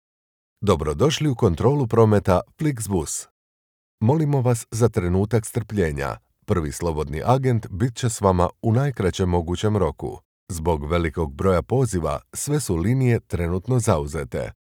IVR
I am a professional Croatian voice-over artist, speaker, narrator and producer with over 20 years of experience with my own studio.
Middle-Aged
BassDeep
WarmAuthoritativeConversationalCorporateFriendly